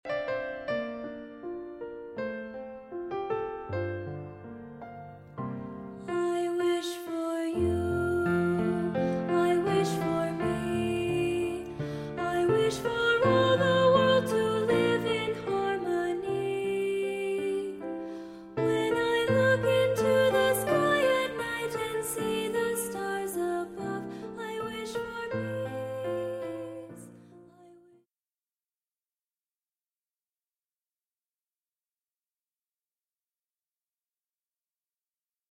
unison choral arrangement